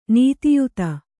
♪ nītiyuta